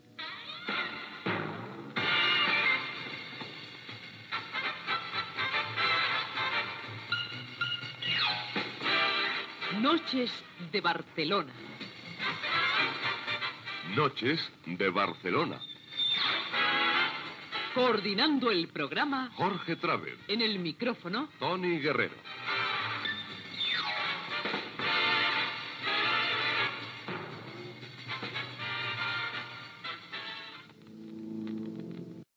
Careta del programa, amb els noms de l'equip